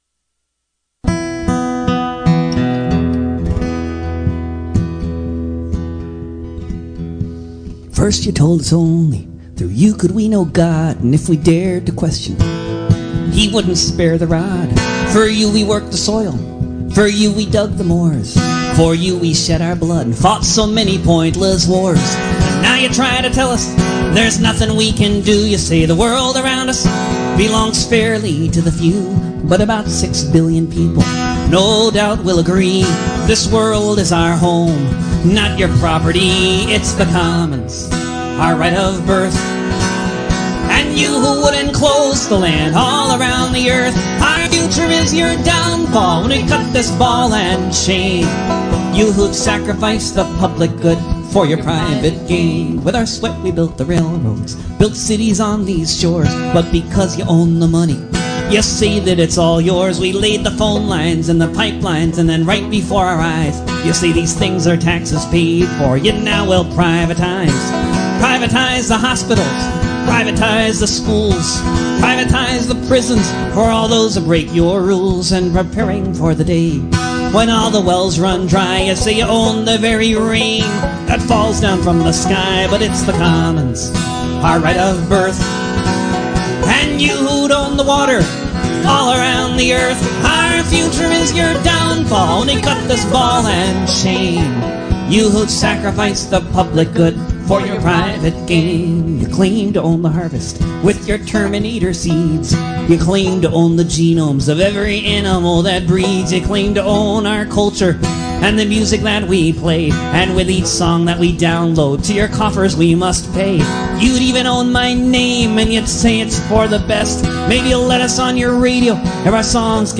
and callers.